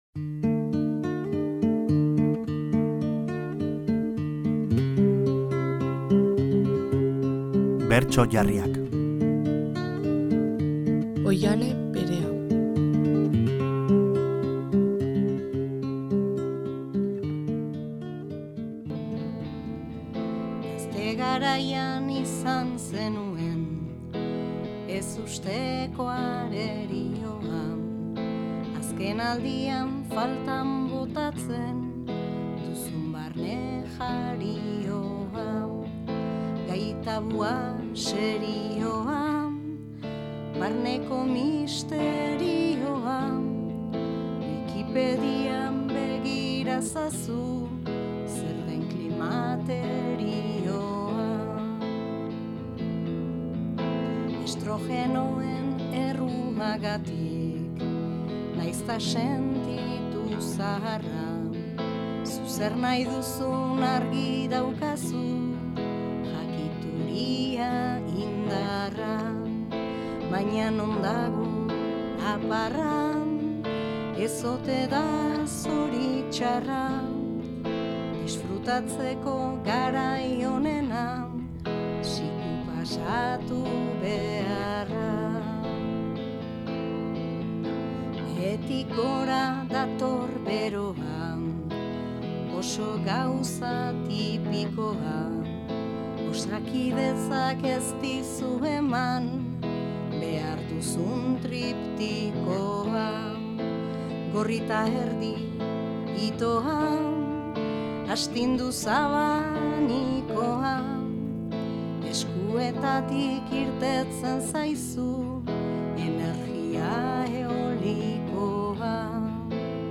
Bertso jarriak